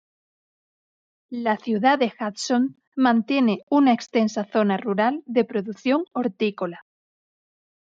Read more Adj Noun Opposite of urbano Frequency C2 Hyphenated as ru‧ral Pronounced as (IPA) /ruˈɾal/ Etymology From Latin rūrālis (“rural”), from rūs (“countryside”) + -ālis.